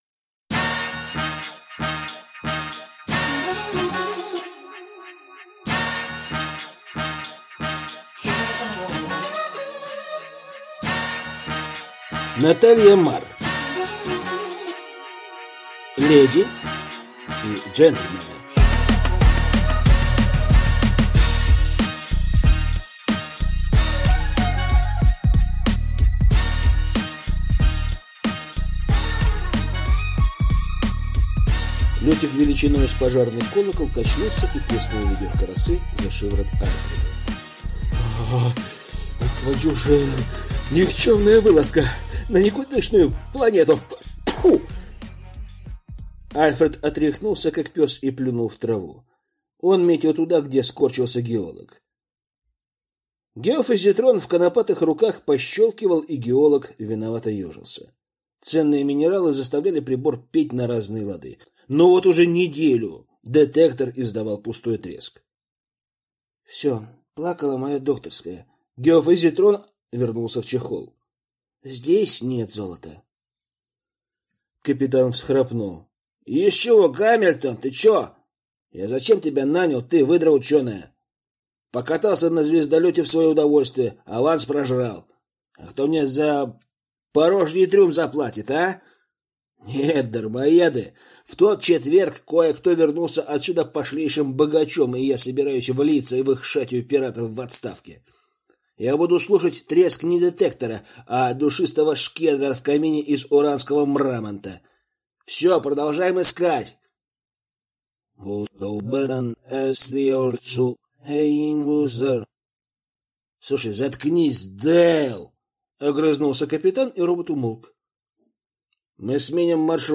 Аудиокнига Леди и джентльмены | Библиотека аудиокниг